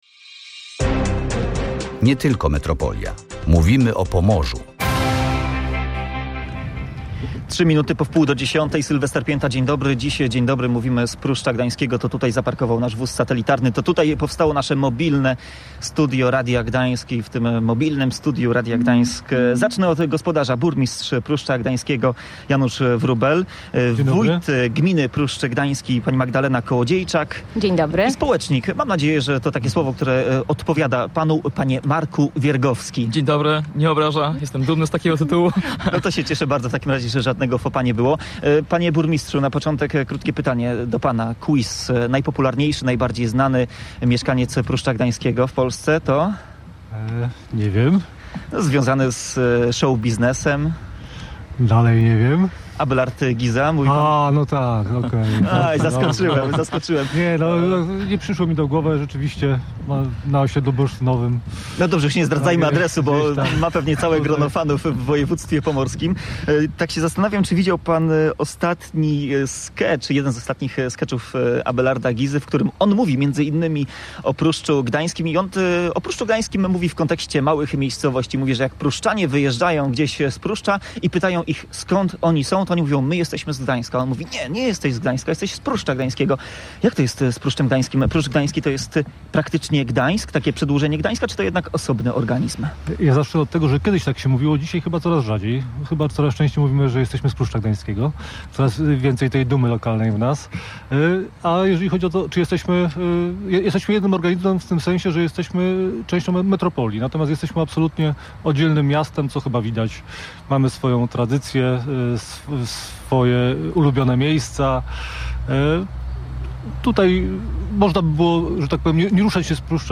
Coraz więcej w nas lokalnej dumy – mówił na antenie Janusz Wróbel, burmistrz Pruszcza Gdańskiego.